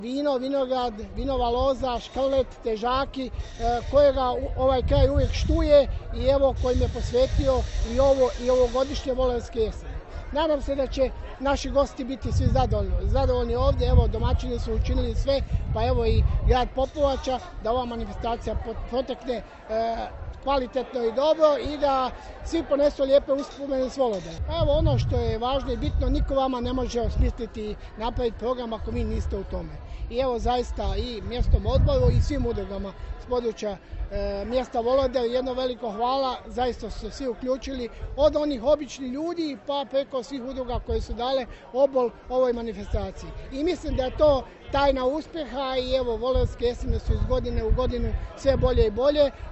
Gradonačelnik Popovače Josip Mišković naglasio je kako je ovo jedna od najznačajnih manifestacija na ovim prostorima, a koja slavi vinogradarstvo i vinarstvo